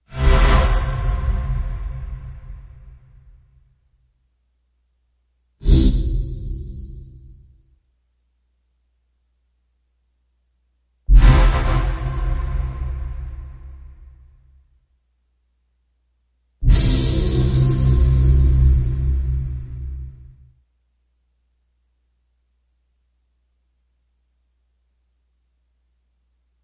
幽灵恐怖效应 - 声音 - 淘声网 - 免费音效素材资源|视频游戏配乐下载
幽灵恐怖效果Woosh，短而长，4种声音变体。它是在FL工作室创建的，使用强大的工具。